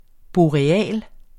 Udtale [ boɐ̯eˈæˀl ]